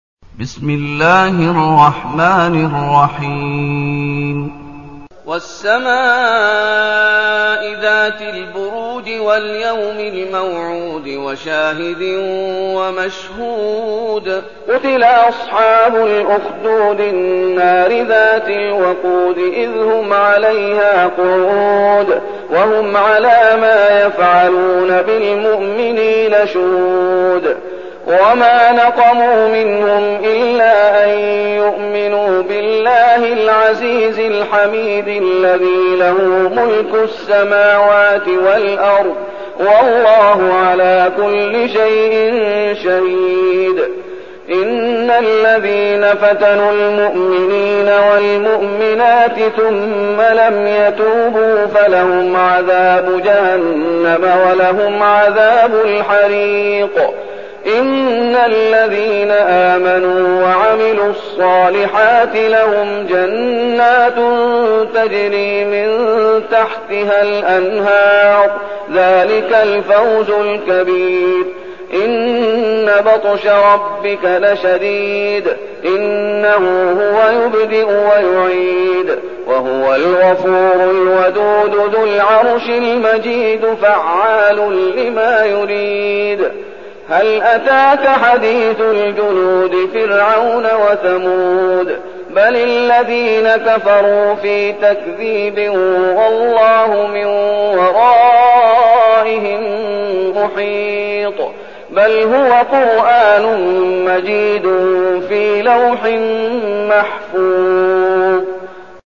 المكان: المسجد النبوي الشيخ: فضيلة الشيخ محمد أيوب فضيلة الشيخ محمد أيوب البروج